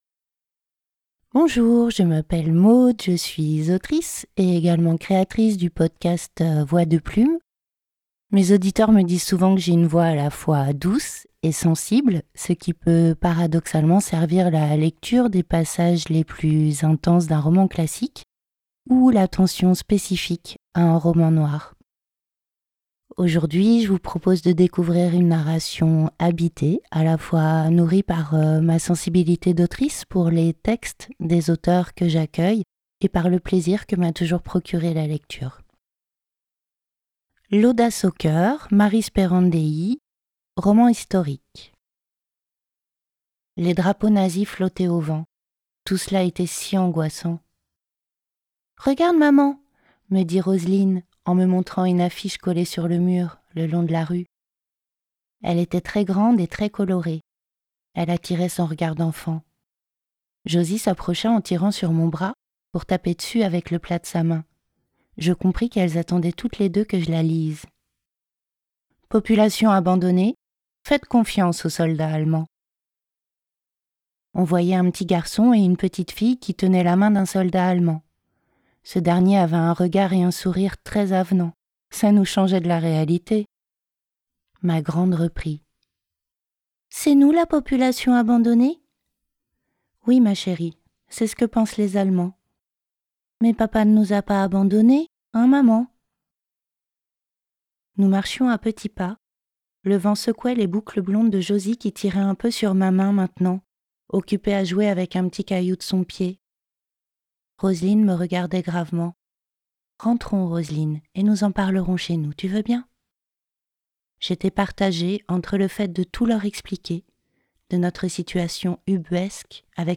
Les extraits ci-dessous explorent différents registres et modes d’interprétation.
Démo complète (5mn)
Vue d’ensemble des registres et intentions